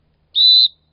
Die Datei „Pfiff“ eignet sich als Sound für Nachrichten
Pfiff.mp3